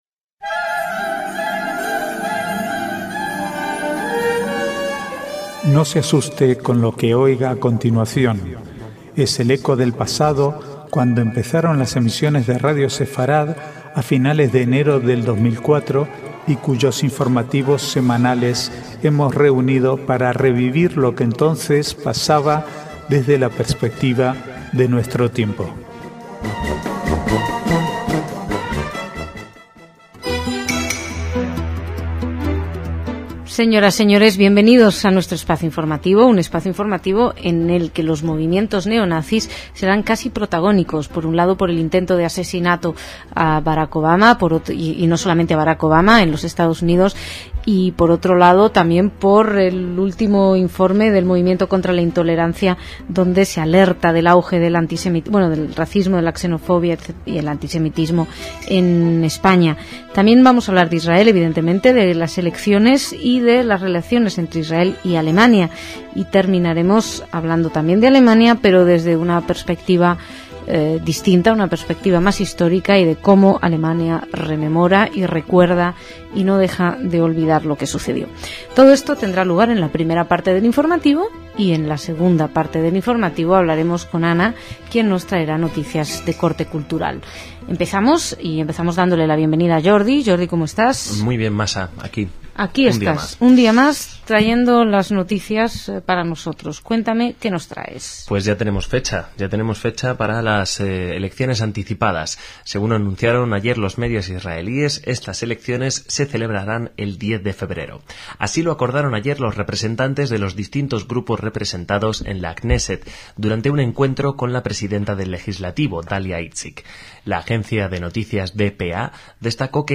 Archivo de noticias del 29 al 31/10/2008